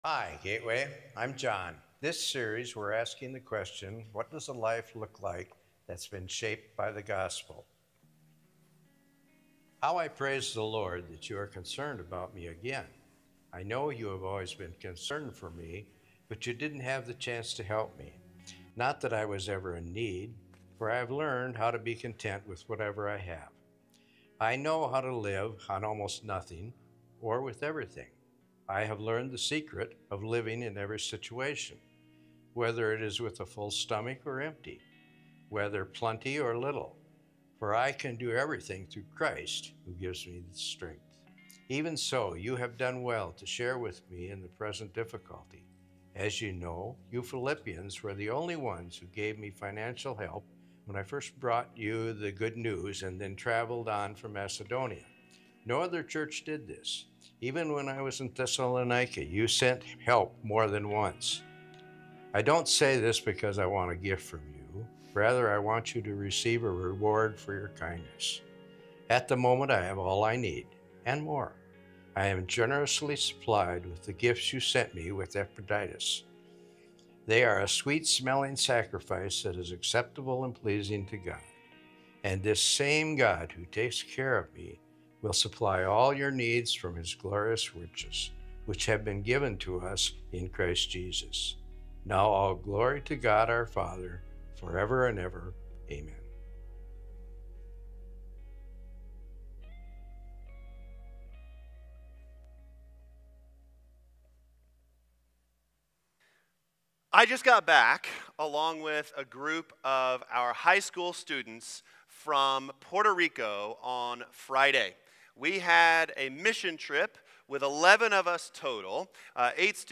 Through-Christ-Celebration-Sunday-Sermon-6.29.25.m4a